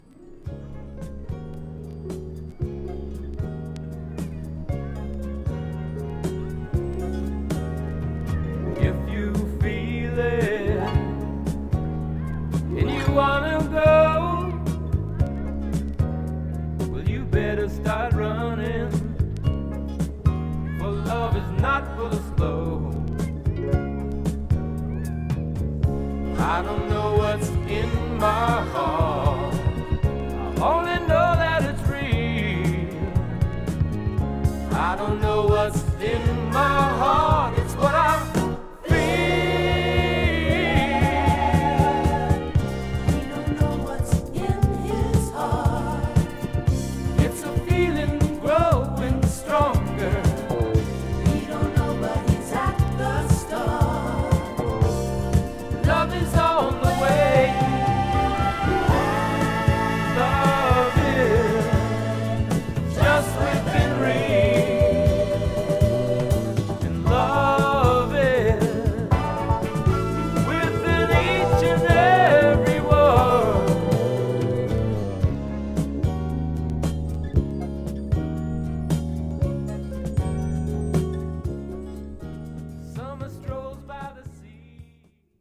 ライトながらねっとりファンク仕立て
黄昏メロウネスにSaxがもり立てる
コンテンポラリー・フィーリング
無名ながら穏やかさがソフティケイトされたアレンジが素晴らしい１枚。